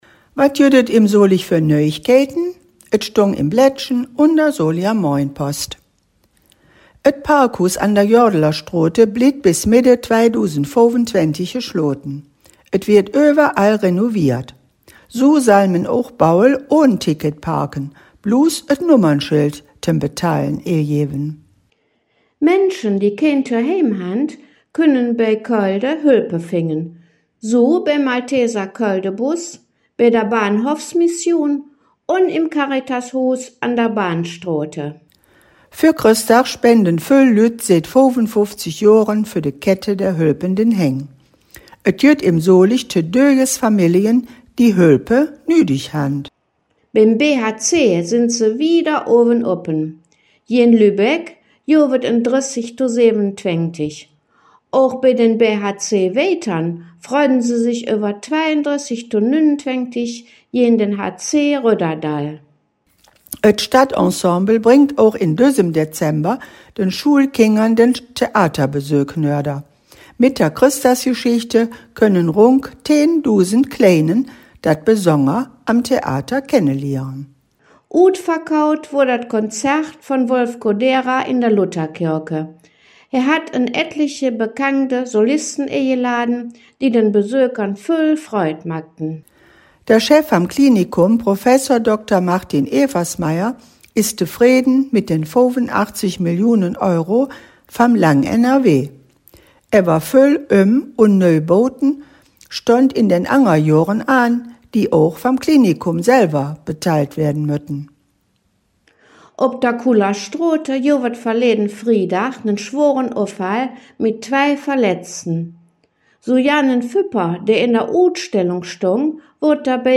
solinger-platt-nachrichten-does-weeke-em-solig-24-51.mp3